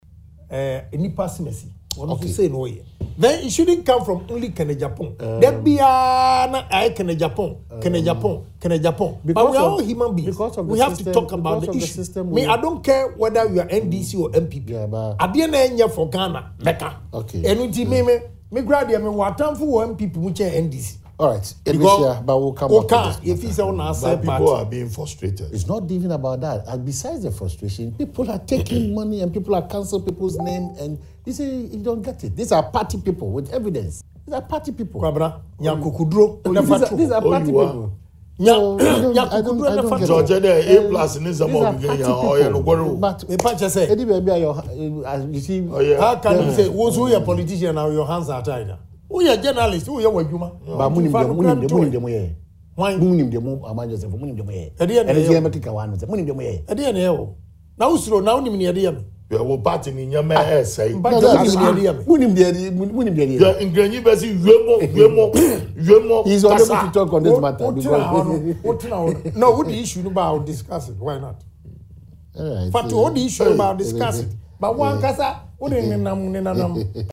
The Outspoken lawmaker was speaking on Adom TV’s Morning Show “Badwam” on Tuesday.